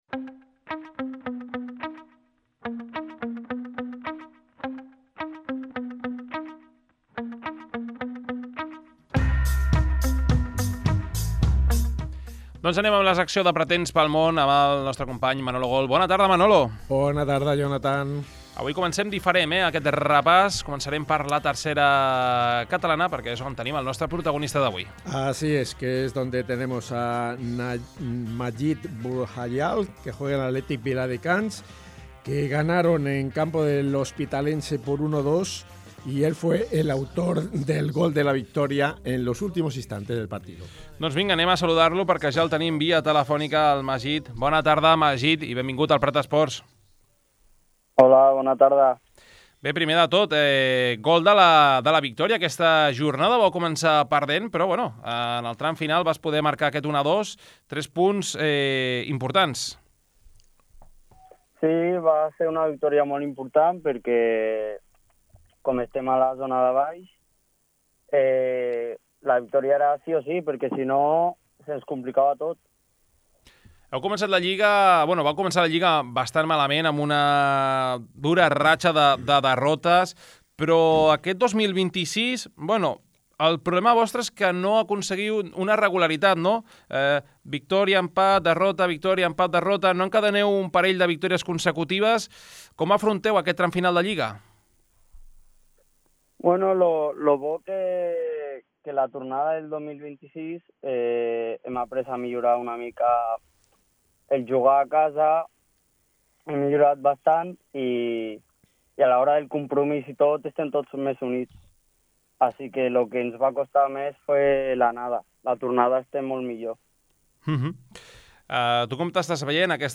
Actualitat esportiva local amb espais d'entrevistes, reportatges i retransmissions en directe.